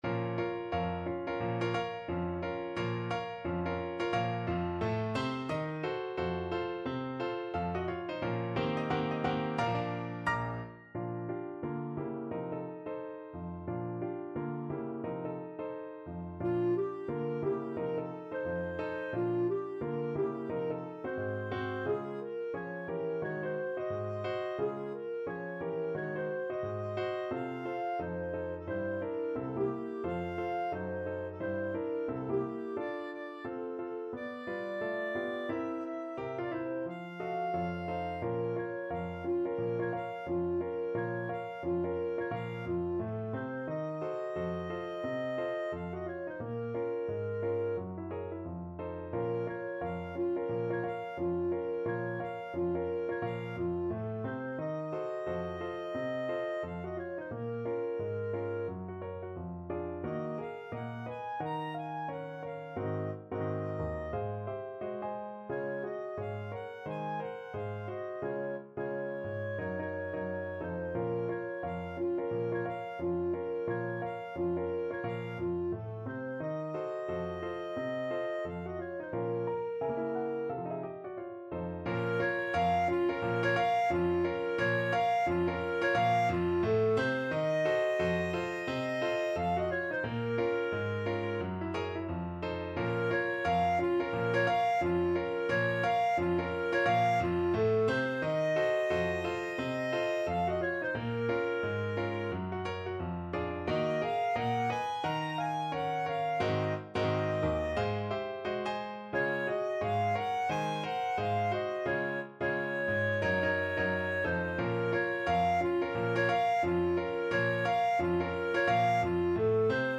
2/2 (View more 2/2 Music)
~ = 176 Moderato